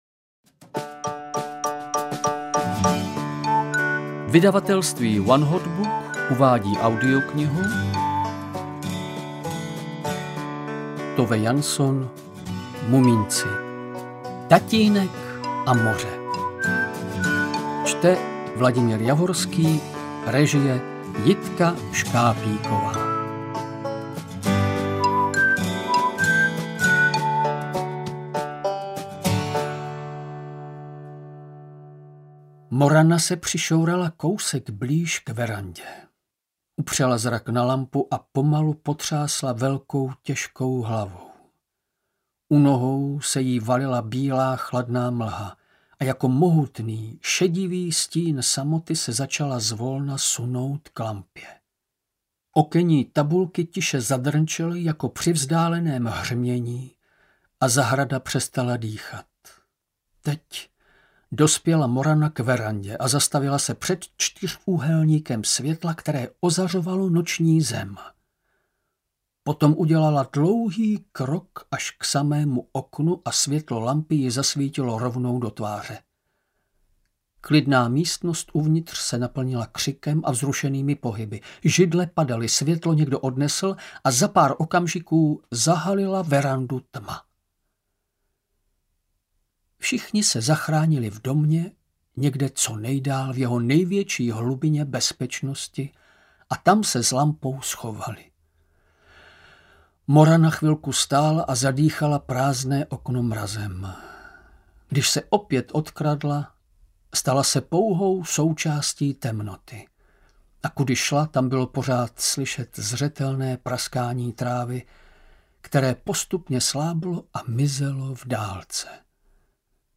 Mumínci: Tatínek a moře audiokniha
Ukázka z knihy
• InterpretVladimír Javorský
muminci-tatinek-a-more-audiokniha